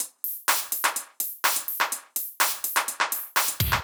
IBI Beat - Mix 3.wav